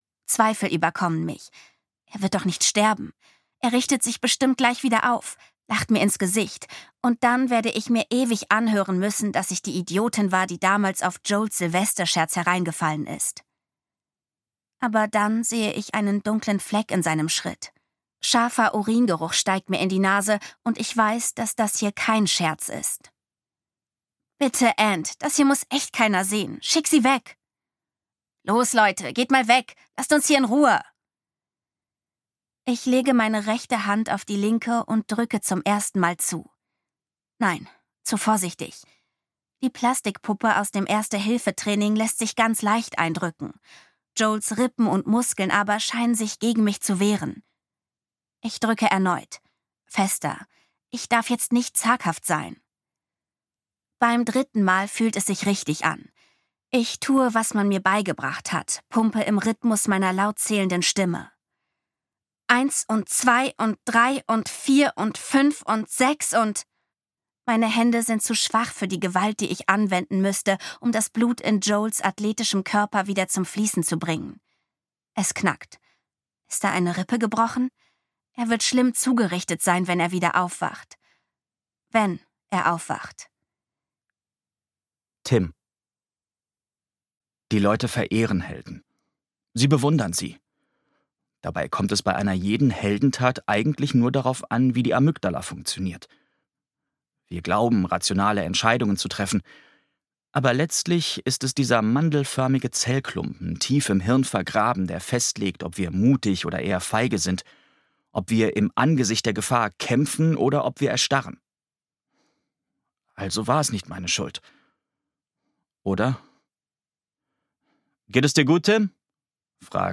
Zwischen zwei Herzschlägen - Eva Carter | argon hörbuch
Gekürzt Autorisierte, d.h. von Autor:innen und / oder Verlagen freigegebene, bearbeitete Fassung.